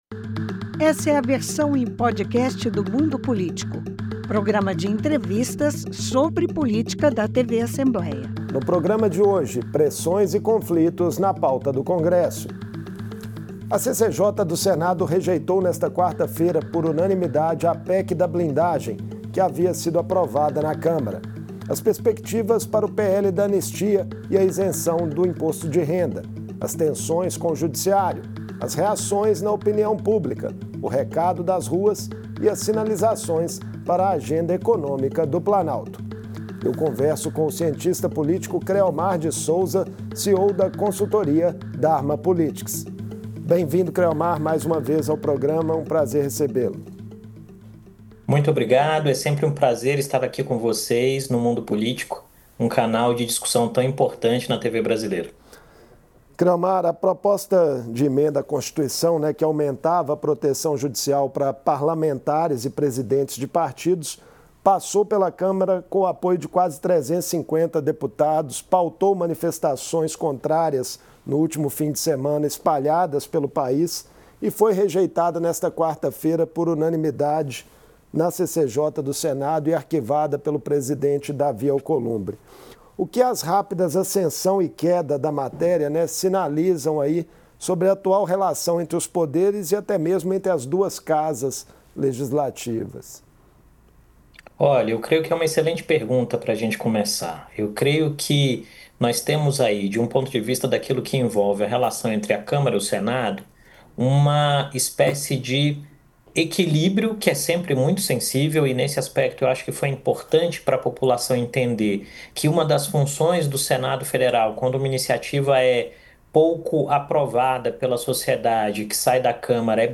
A CCJ do Senado enterrou a PEC da blindagem ao rejeitar a proposta por unanimidade e o presidente da Casa, senador Davi Alcolumbre, anunciar o arquivamento. Na véspera, a Câmara havia aprovada a matéria no Plenário por ampla maioria. Em entrevista